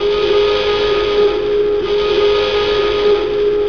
regularSiren.ogg